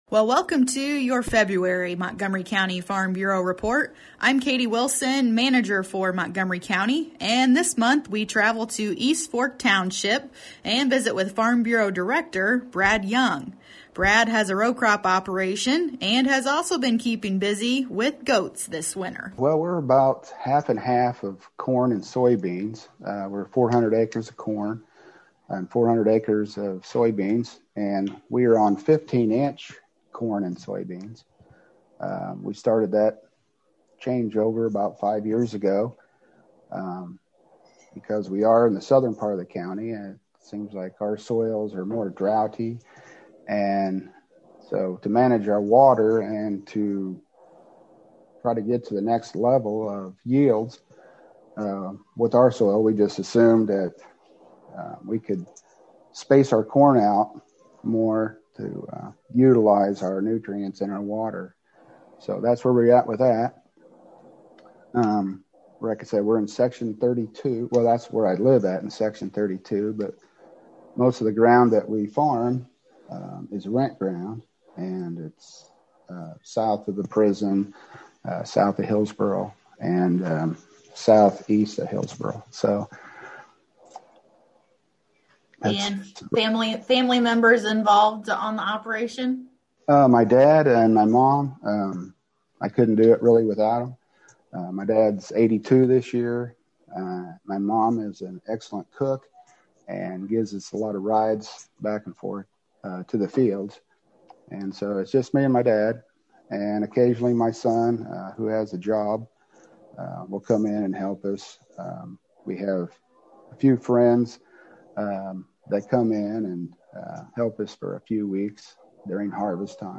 Podcasts - Interviews & Specials